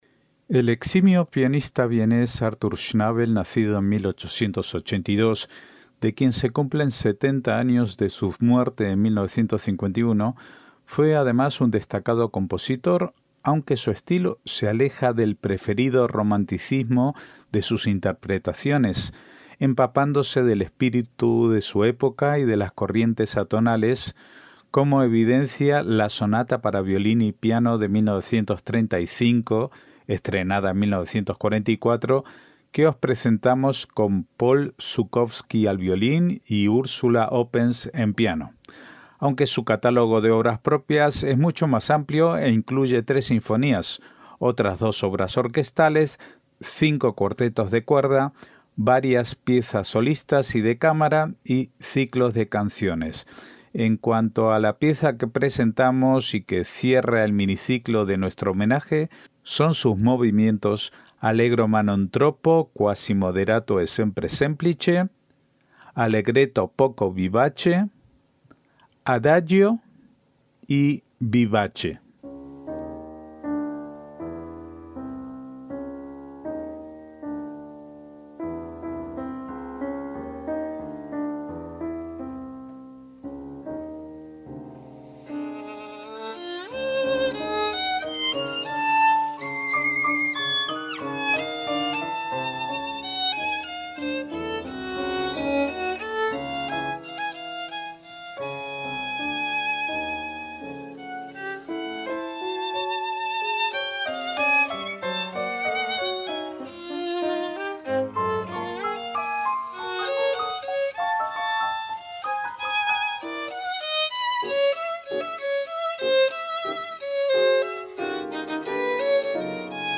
MÚSICA CLÁSICA
corrientes atonales